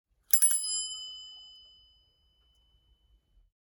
Разные велосипедные звуки: колеса и трещотки велосипедные, езда на велосипеде, звонок, тормоза, цепи.
4. Тот же звонок, но прокрутили несколько раз
zvonok-velosipeda-2.mp3